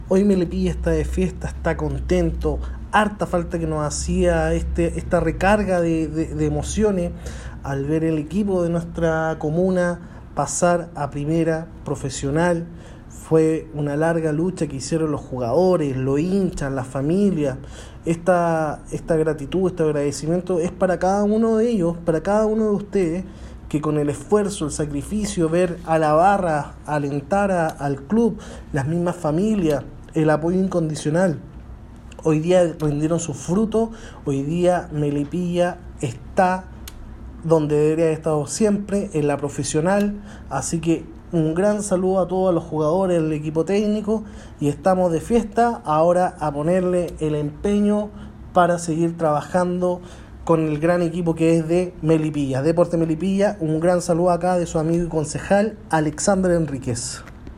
Por su parte, autoridades y candidatos para las elecciones de abril próximo quisieron entregar su saludo al programa radial Entre Portales:
Alexander Henríquez, concejal de Melipilla por Renovación Nacional y va a la reelección